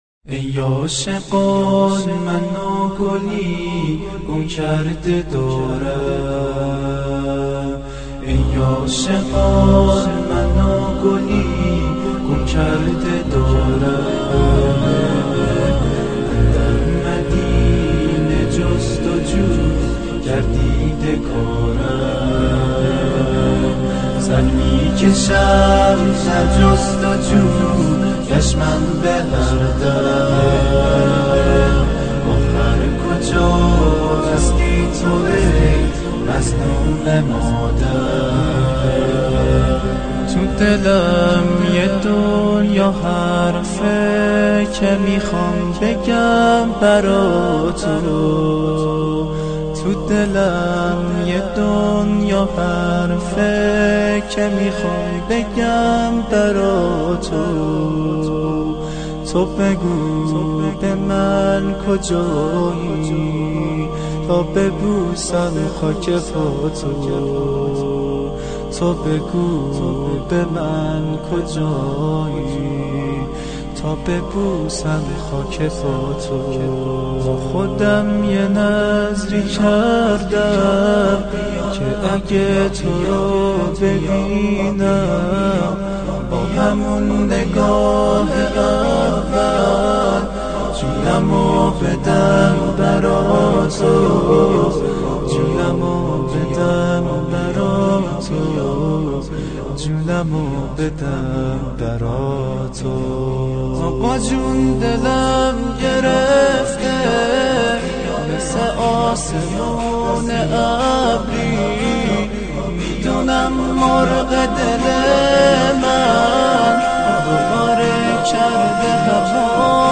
تواشیع 1